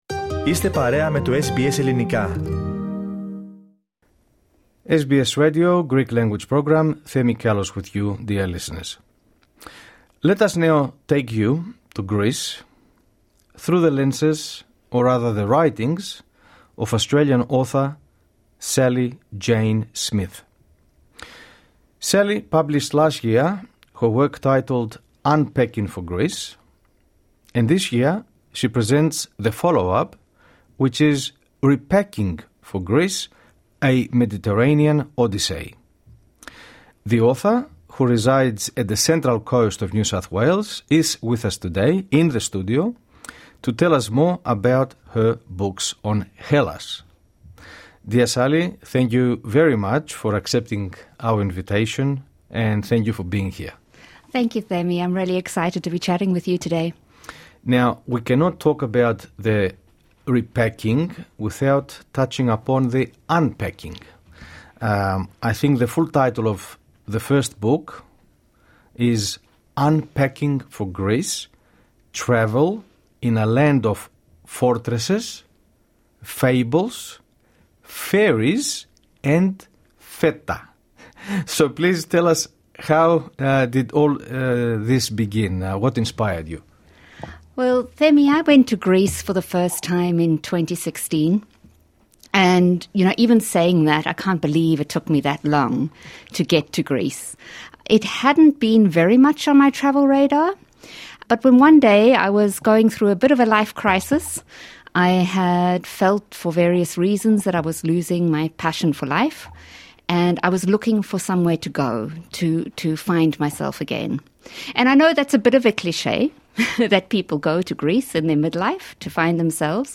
In this interview to SBS Greek the Central Coast author tells us what inspired her for the writings, explains why travelling to Greece was an experience of life-changing journey, elaborates on what fascinated her most of the country, shares some of the memorable encounters and stories from her travels in Greece, particularises on the differences and similarities between the two books, and reveals if there is going to be a third book!